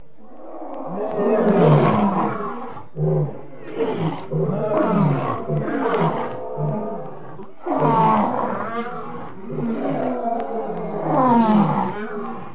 دانلود صدای حیوانات جنگلی 102 از ساعد نیوز با لینک مستقیم و کیفیت بالا
جلوه های صوتی